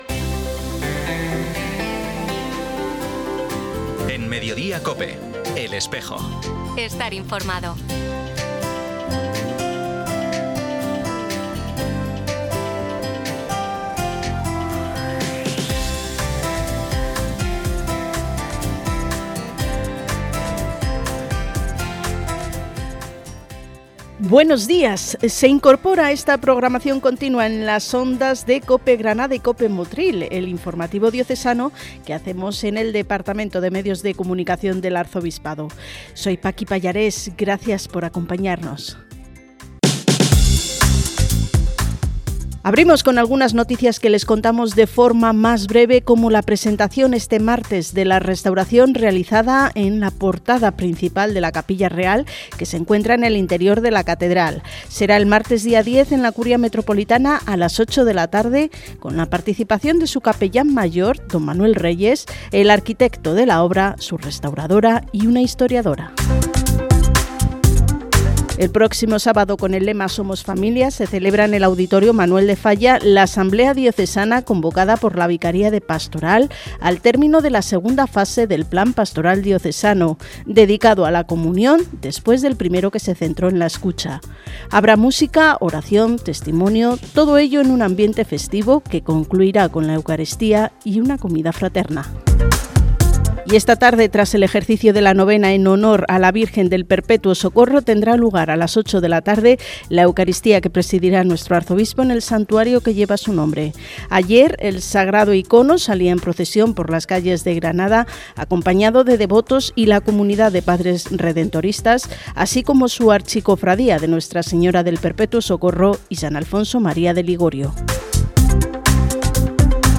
Programa emitido en COPE Granada y COPE el 8 de junio, en el informativo diocesano “Iglesia Noticia”.